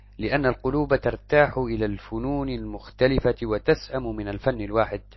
ch_00_arabic_tts_dataset_24.mp3